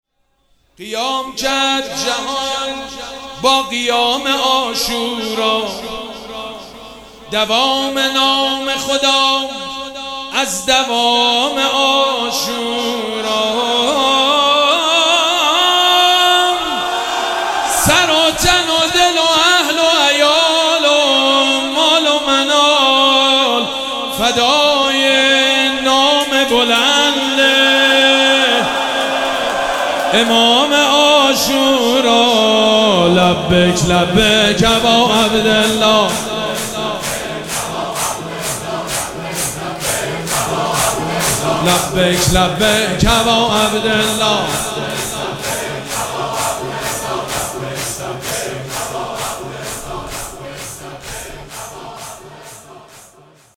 مراسم عزاداری شب دوم محرم الحرام ۱۴۴۷
حسینیه ریحانه الحسین سلام الله علیها
شعر خوانی